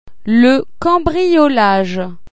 Le voleur   cha-o